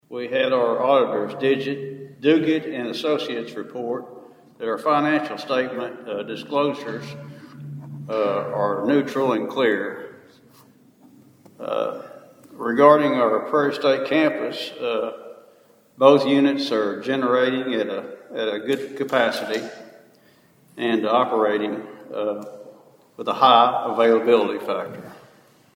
The Princeton City Council’s meeting Monday night was brief but packed with positive updates, as department heads shared news of new hires, ongoing projects, and community events.